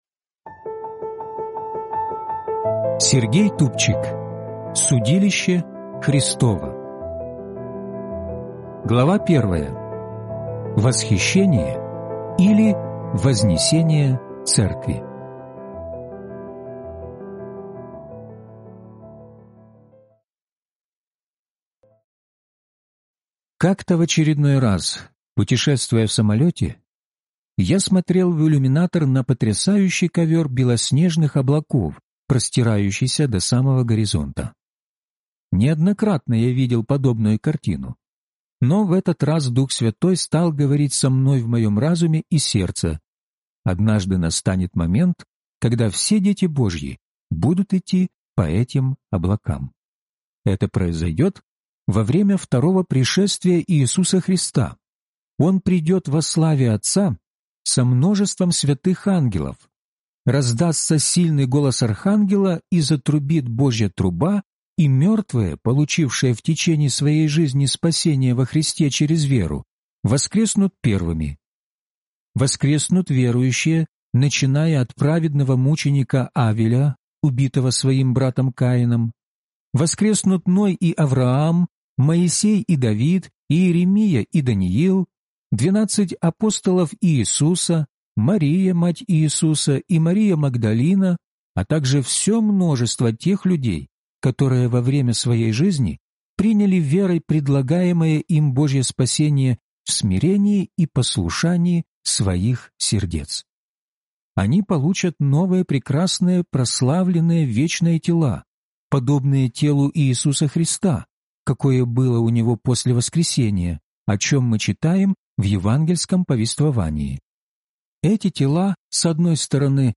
Судилище Христово (аудиокнига)